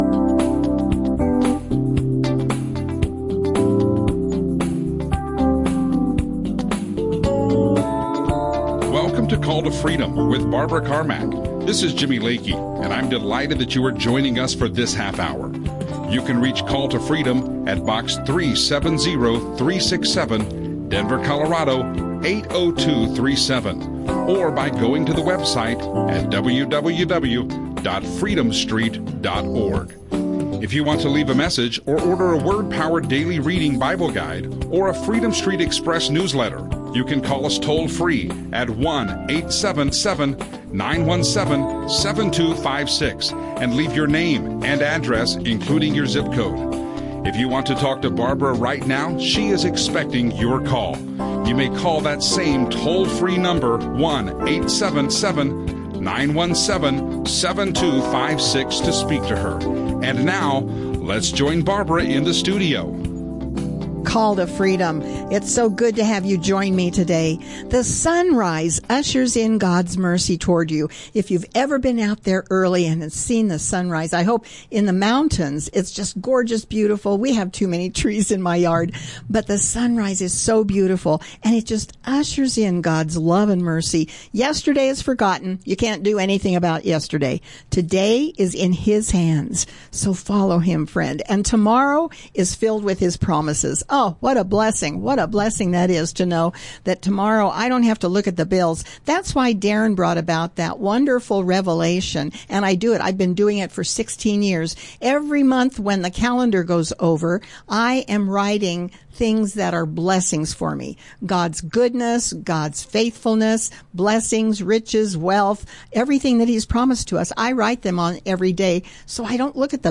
Bible teaching Call to Freedom